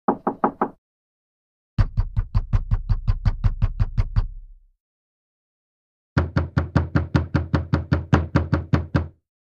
Звуки стука в дверь
Тихие стуки в дверь — 2 вариант